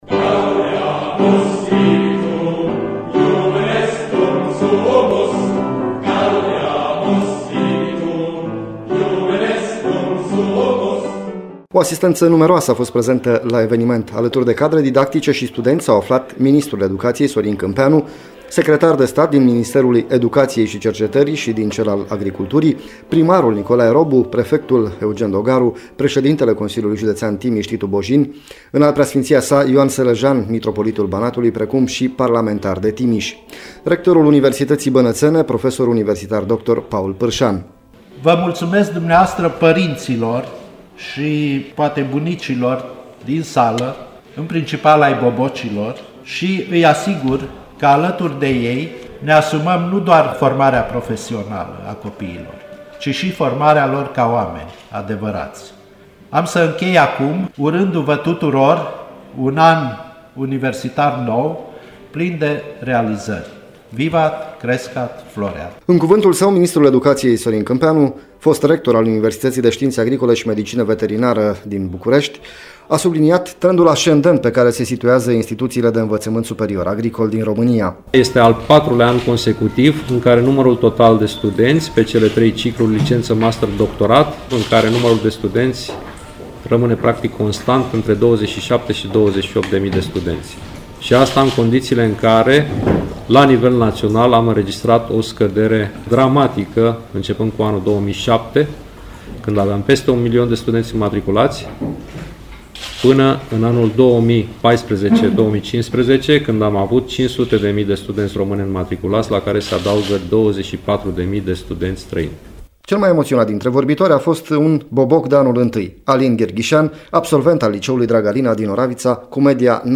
Mii de studenți au început luni noul an universitar la Universitatea de Științe Agricole și Medicină Veterinară Regele Mihai I al României din Timișoara. Alături de studenți și cadre didactice au venit și multe oficialități la deschiderea anului universitar.
Ministrul Educației, Sorin Câmpeanu, remarca trendul ascendent pe care se situază instituțiile de învățământ superior agricol din țară.